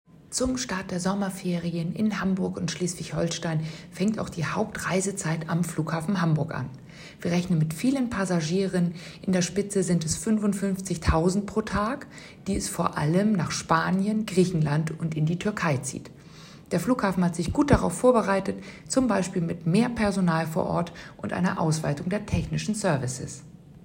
O-Töne: